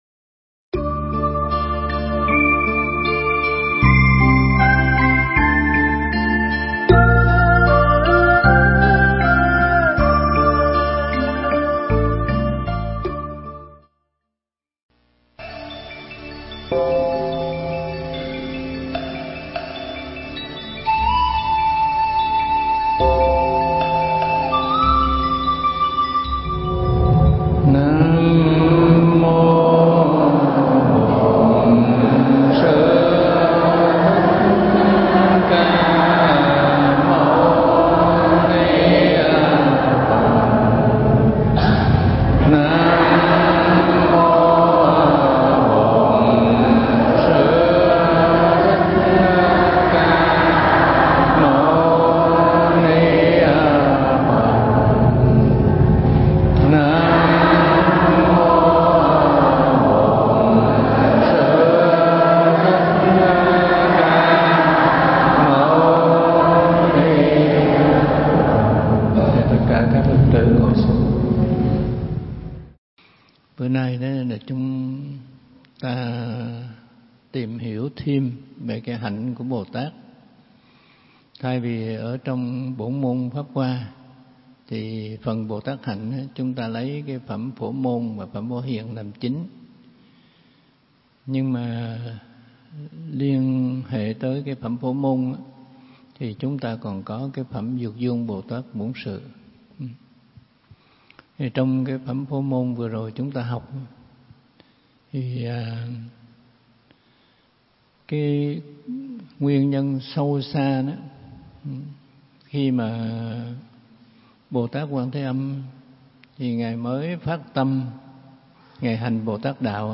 Nghe Mp3 thuyết pháp Bổn Môn Pháp Hoa phần 24 – Thích Trí Quảng
Mp3 Pháp Thoại Bổn Môn Pháp Hoa phần 24 – Hòa Thượng Thích Trí Quảng giảng tại Chùa Huê Nghiêm, Quận 2, (ngày 24 tháng 4 năm Quý Tỵ), ngày 2 tháng 6 năm 2013